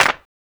• 2000s Short Reverb Clap Single Shot G# Key 01.wav
Royality free hand clap sound - kick tuned to the G# note. Loudest frequency: 2186Hz